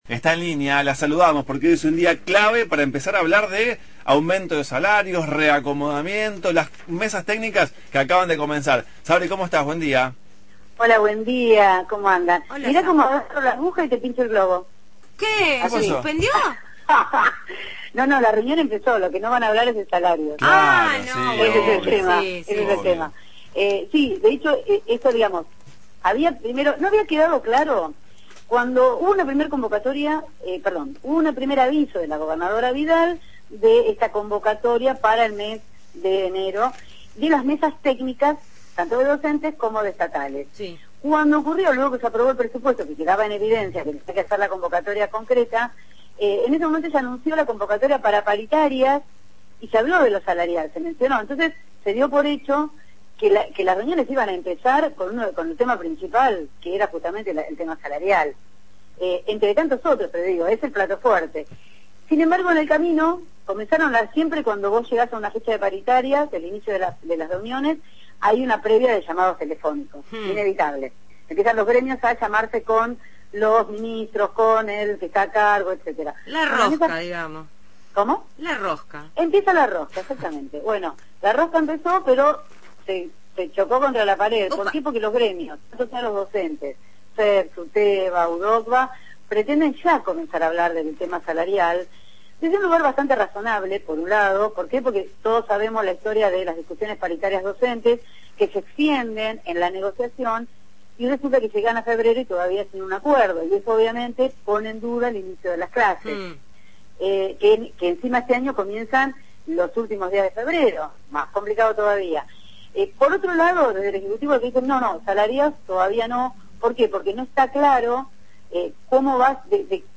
realizó su habitual informe sobre la actualidad política bonaerense. En esta oportunidad se refirió al inicio de las mesas técnicas paritarias, y a encuentros de distintos sectores del FpV: uno, se realizará en Santa Teresita este fin de semana; otro, en Capital el miércoles próximo.